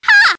One of Daisy's voice clips in Mario Kart 7